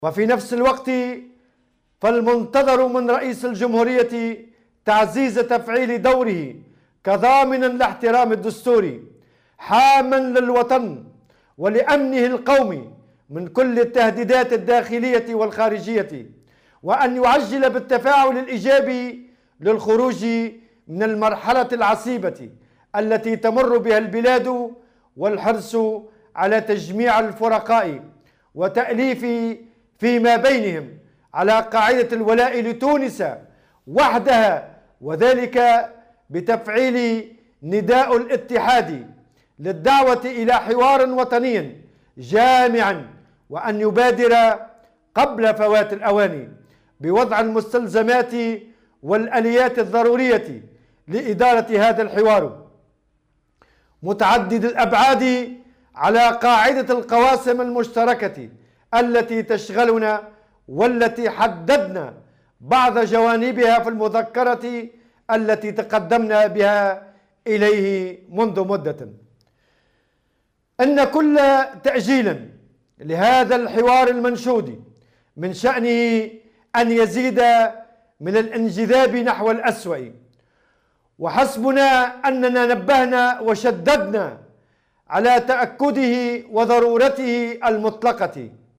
وأضاف في كلمة ألقاها اليوم بمناسبة عيد الشغل، أنه على رئيس الجمهورية تفعيل نداء الاتحاد و الدعوة إلى حوار وطني جامع والمبادرة بوضع المستلزمات والآليات الضرورية لإدارته قبل فوات الأوان.